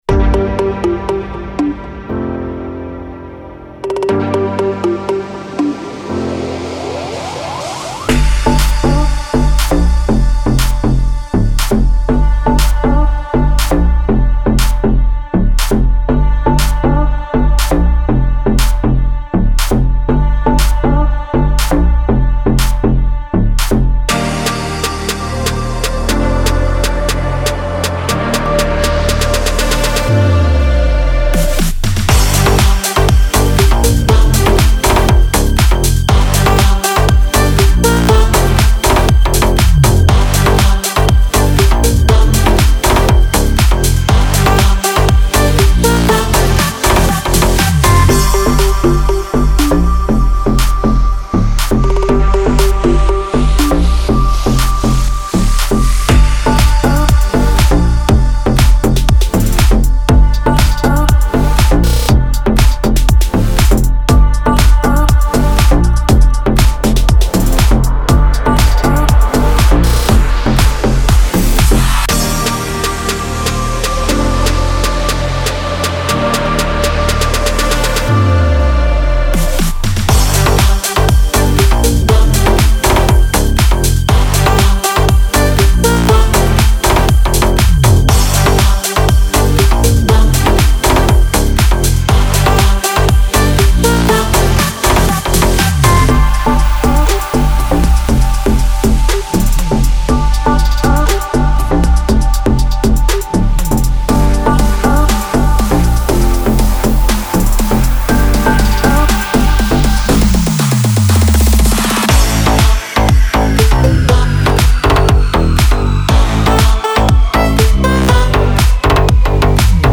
Темп песни: быстрый.
• Минусовка